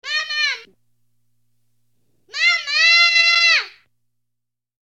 孩子大声喊妈妈音效_人物音效音效配乐_免费素材下载_提案神器